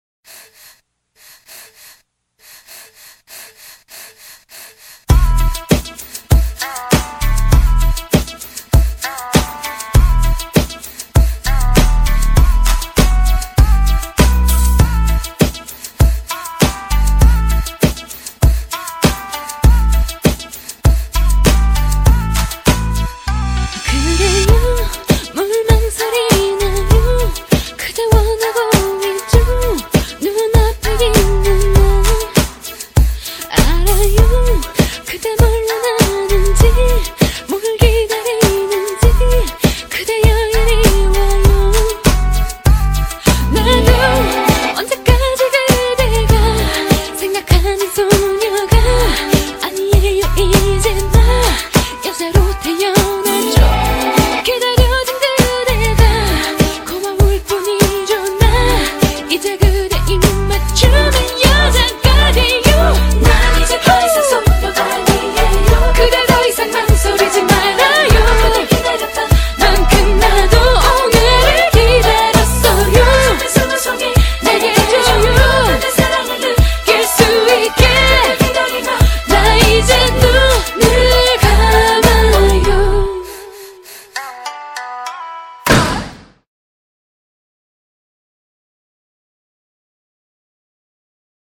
BPM99--1
Audio QualityPerfect (High Quality)